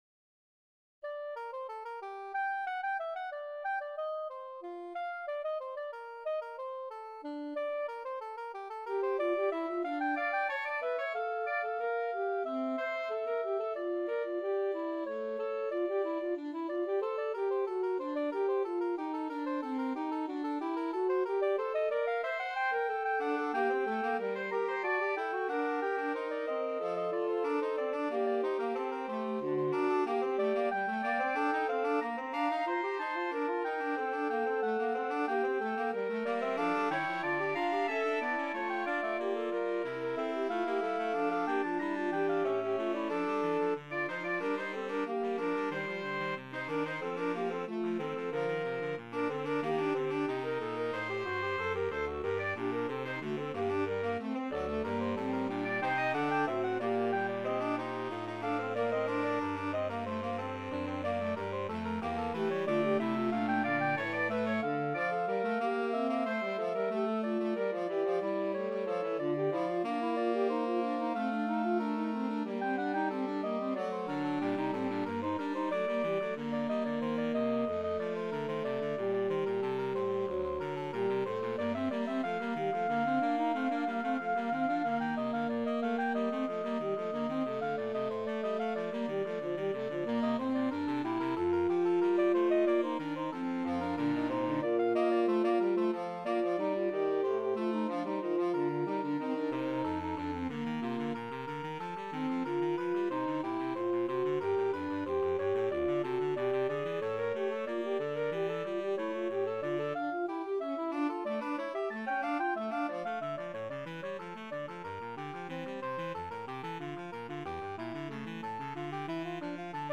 4/4 (View more 4/4 Music)
Advanced Level: Recommended for Advanced Players
Classical (View more Classical Saxophone Quartet Music)